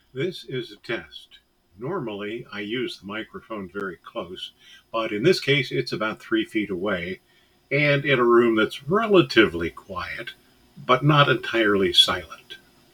TechByter Worldwide is recorded in a room that was never intended to be a studio, but I place the microphone close and have some sound conditioning foam in place. I made a test recording with the microphone about 3 feet away and this is the result:
As a result, room noise and echo were apparent, although still within the acceptable range.